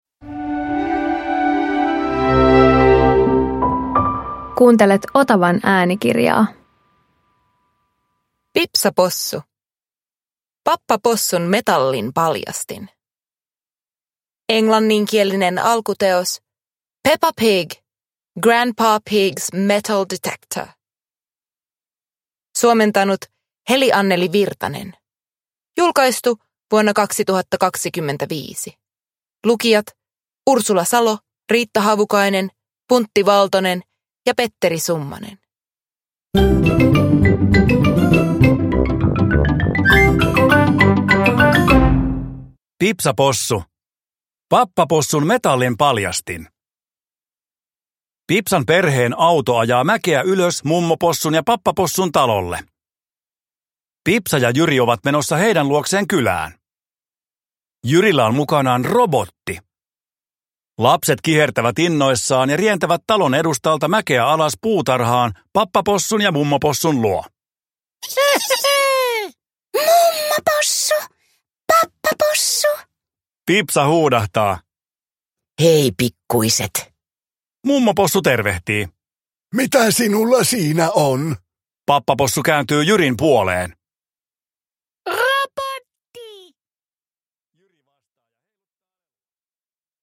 Pipsa Possu - Pappapossun metallinpaljastin – Ljudbok